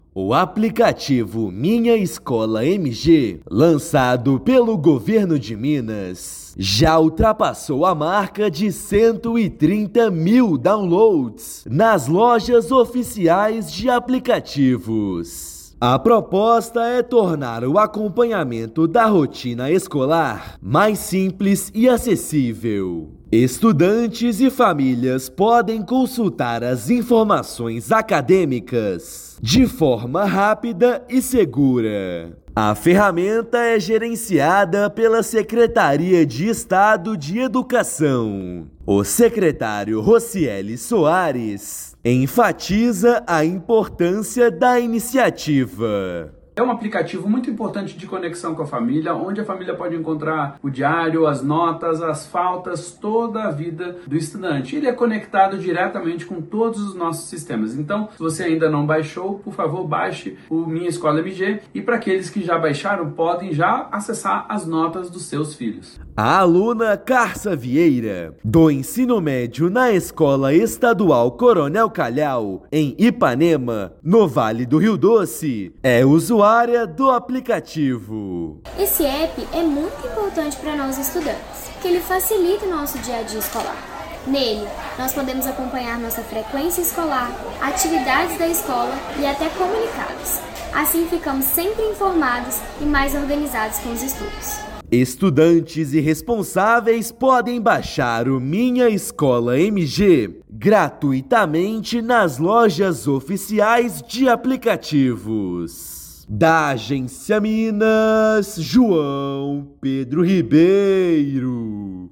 Ferramenta do Governo de Minas amplia o acesso de estudantes e responsáveis às informações da vida escolar e fortalece a comunicação com a rede estadual de ensino. Ouça matéria de rádio.